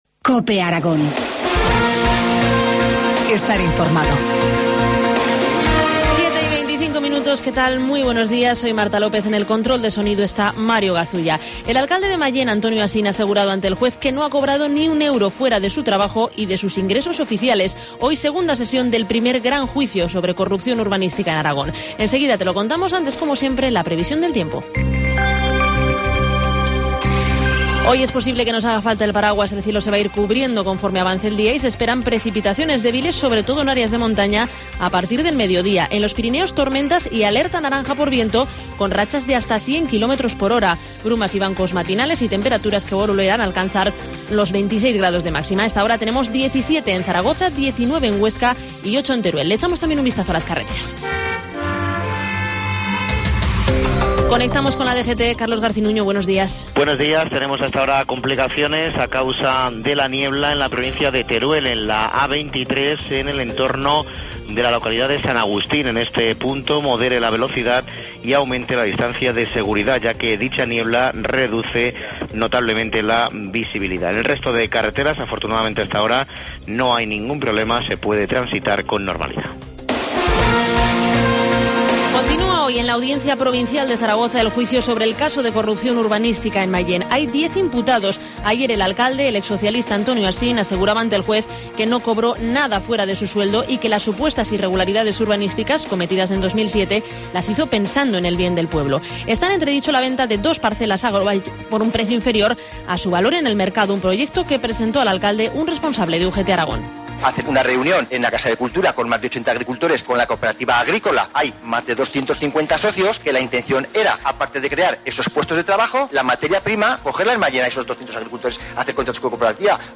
Informativo matinal, martes 22 de octubre, 7.25 horas